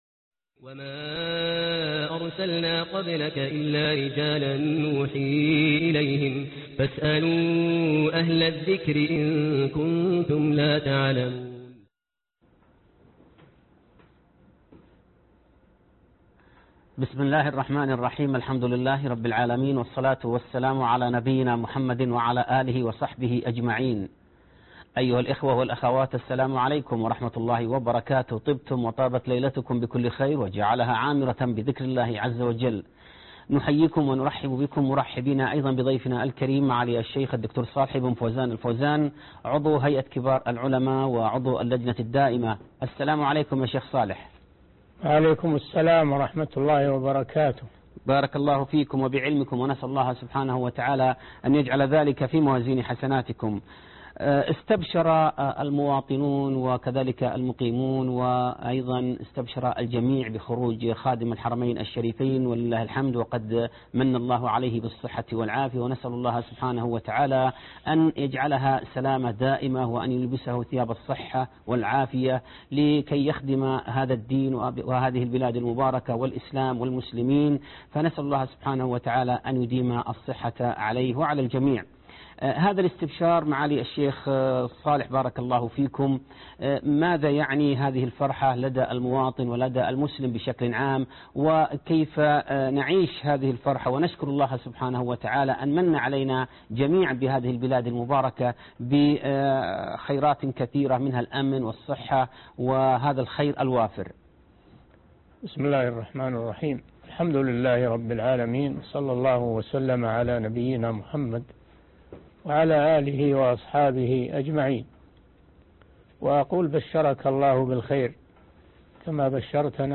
فتاوي (14/1/1434 هـ)(فتاوي علي الهواء) - الشيخ صالح بن فوزان الفوازان